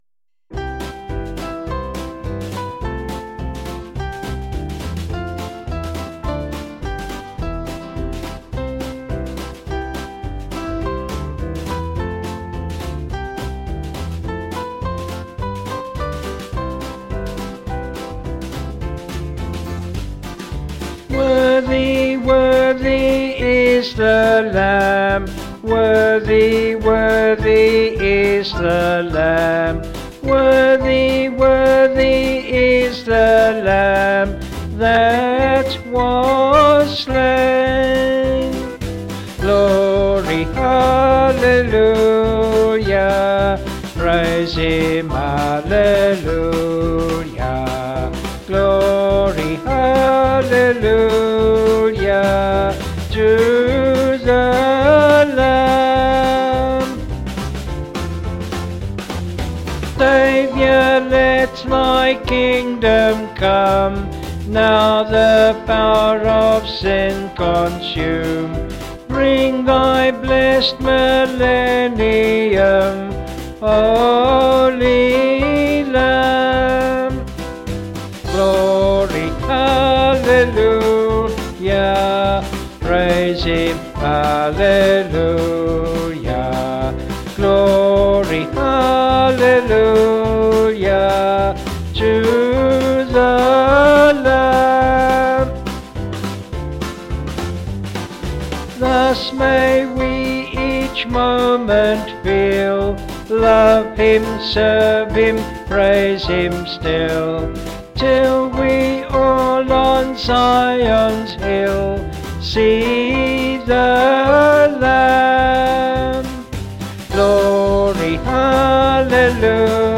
Vocals and Organ   264.8kb Sung Lyrics 1.8mb
Vocals and Band   264.7kb Sung Lyrics